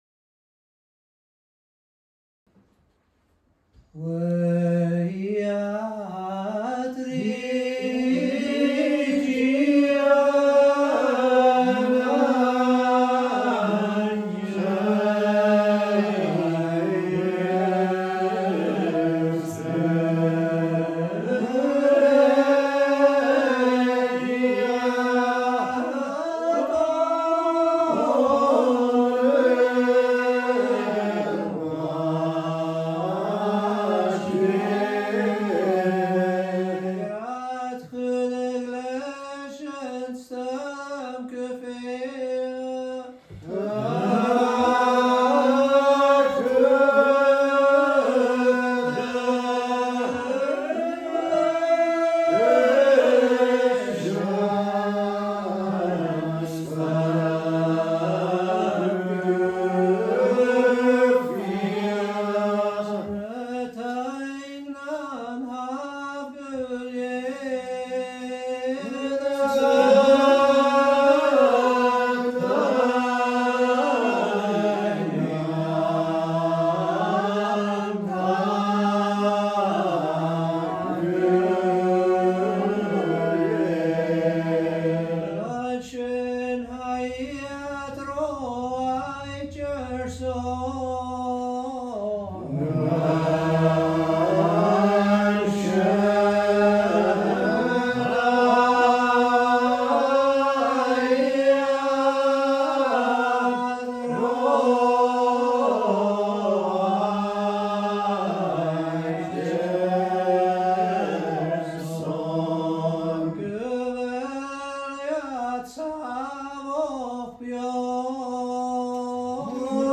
A’ Seinn nan Sailm Gaelic Psalmody